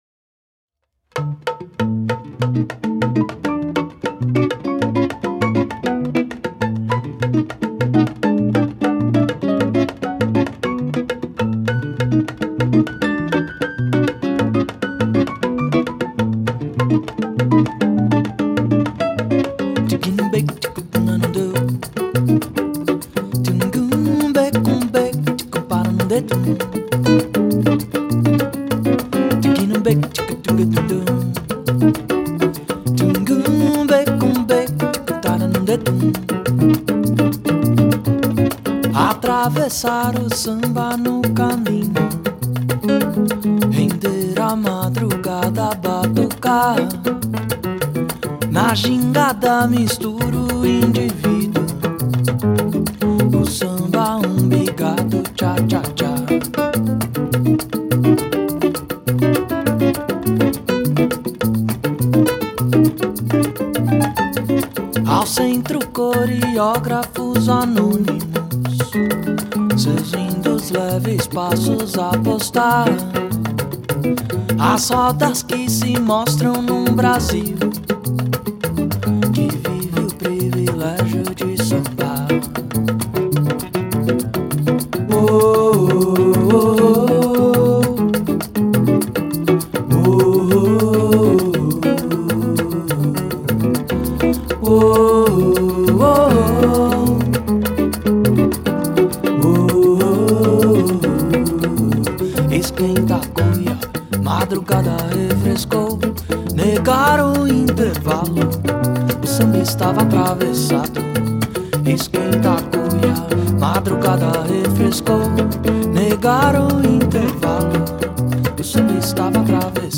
Brazilian guitarist, singer and composer
pianist, piano accordionist and composer
multi-percussionist